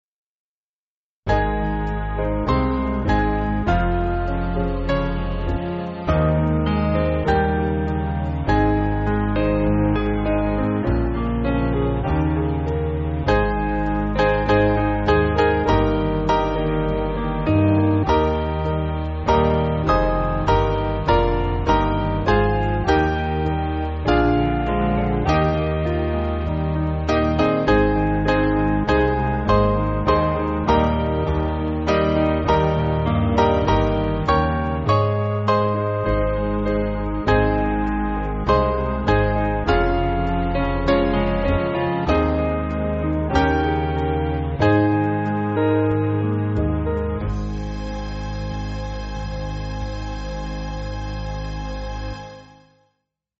Small Band
(CM)   1/Ab